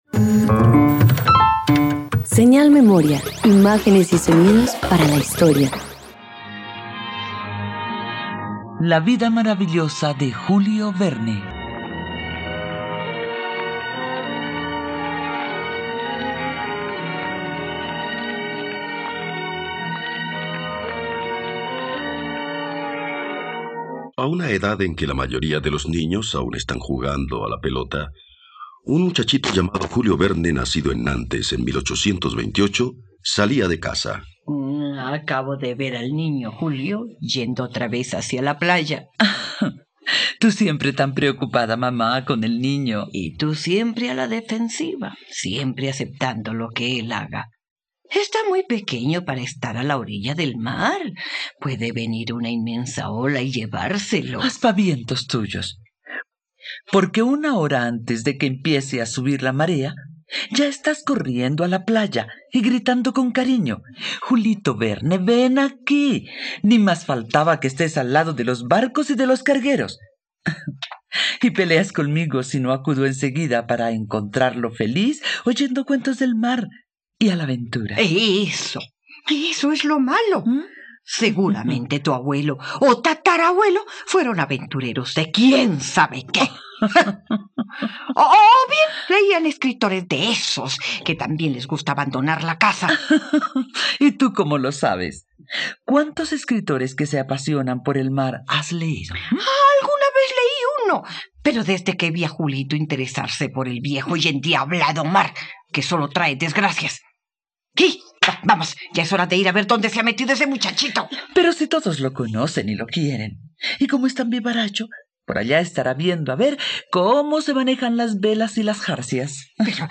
La vida maravillosa de Julio Verne - Radioteatro dominical | RTVCPlay
..Radioteatro. Disfruta una adaptación radiofónica de la vida del escritor, poeta y dramaturgo francés Julio Verne.